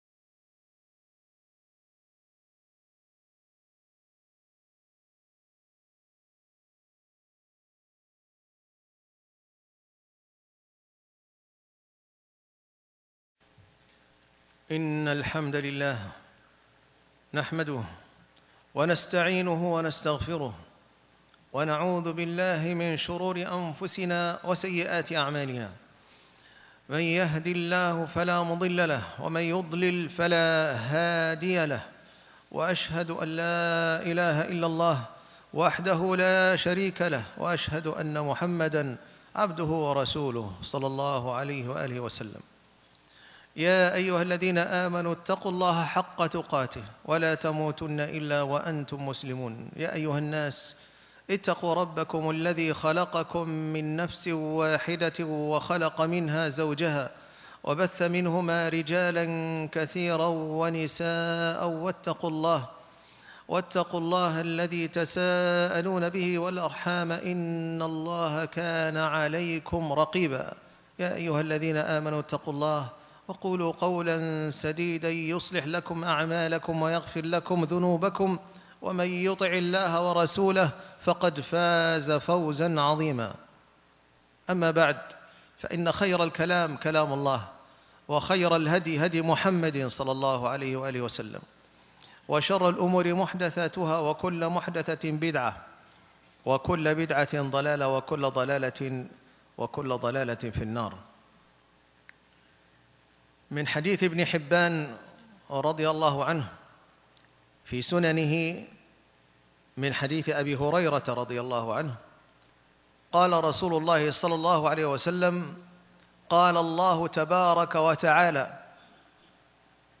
أنوار الصلاة - خطبة الجمعة